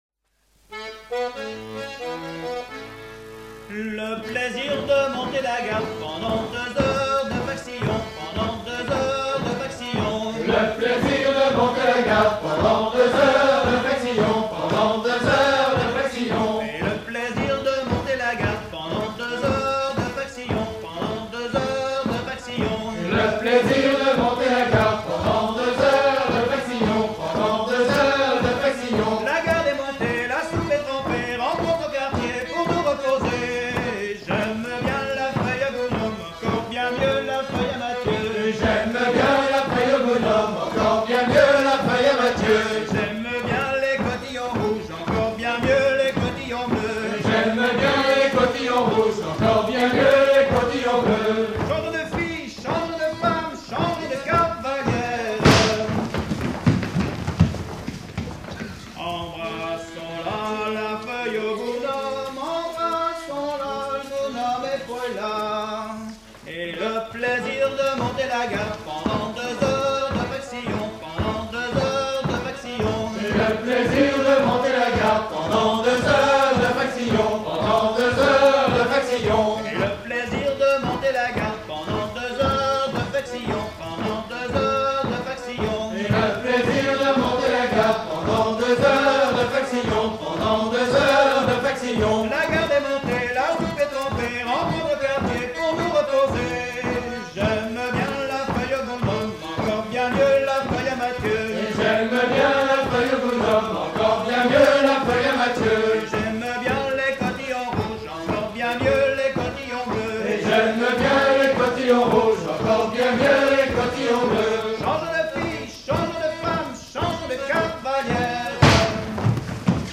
danse-jeu
Dix danses menées pour des atelirs d'apprentissage
Pièce musicale inédite